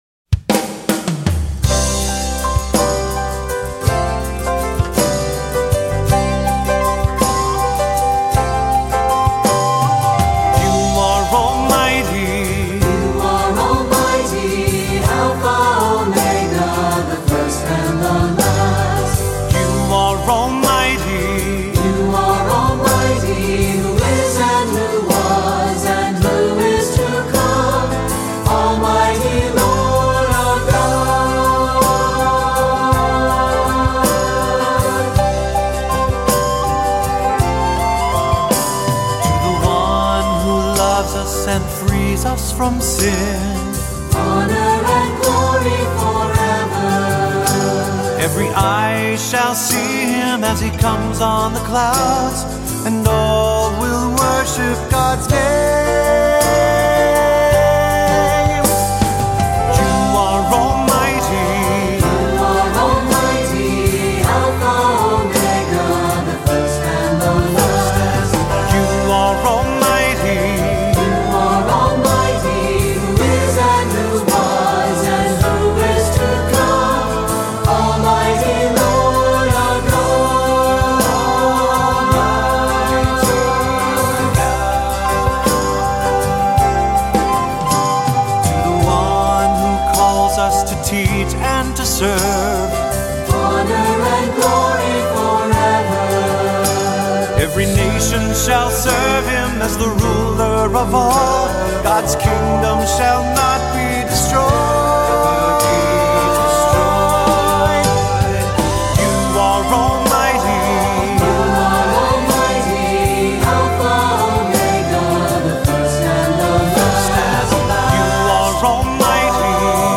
Voicing: Assembly, cantor